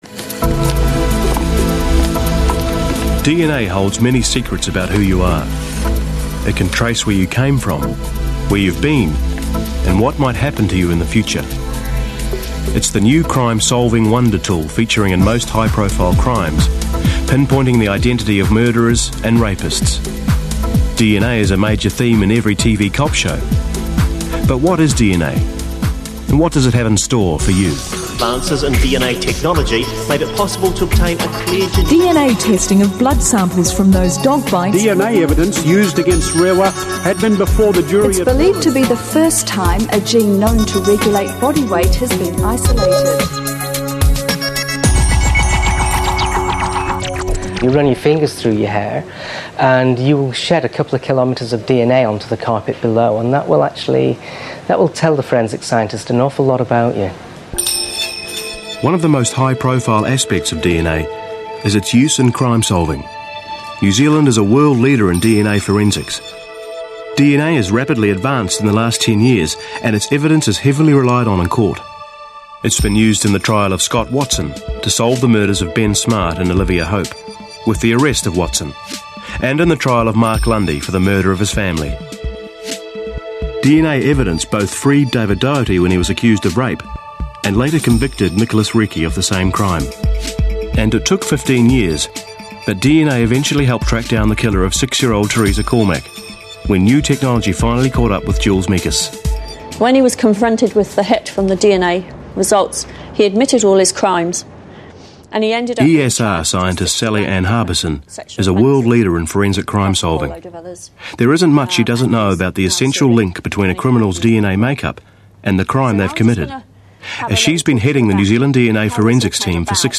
Voice Sample: Voice Demo
We use Neumann microphones, Apogee preamps and ProTools HD digital audio workstations for a warm, clean signal path.